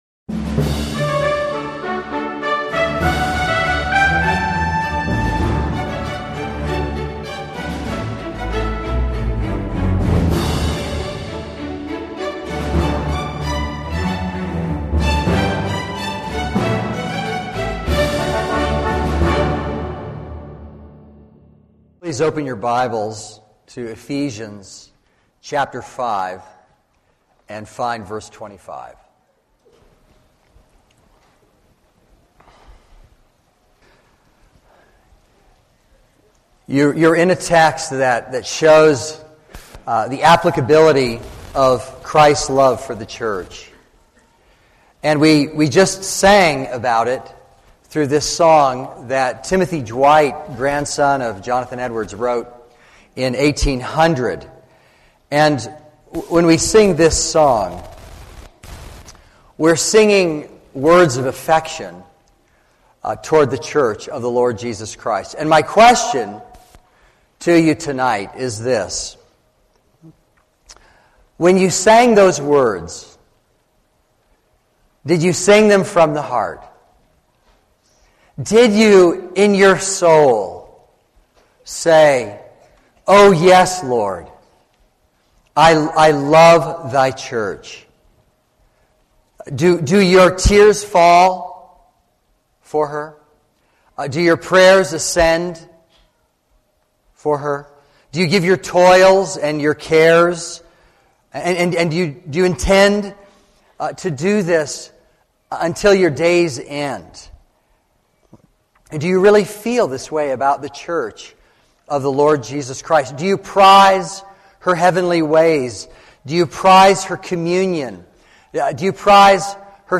And my prayer for all of us here at this conference is that if anyone could not have sung that song with sincerity tonight, that somehow by the time we've made our way through the conference, that you will be able to sing it with all of your heart.